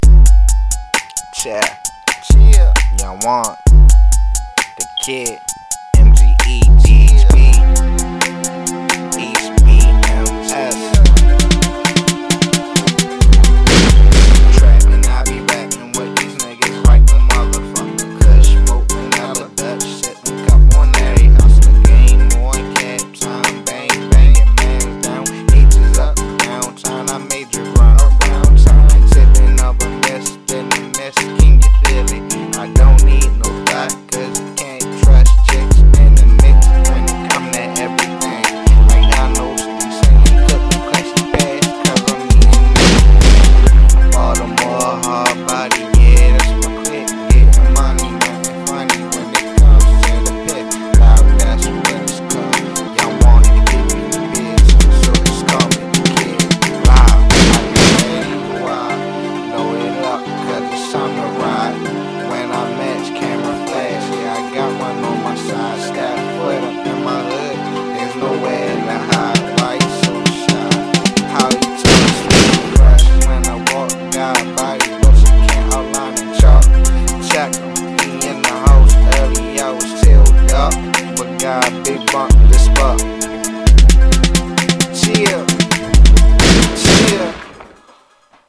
It's Freestyled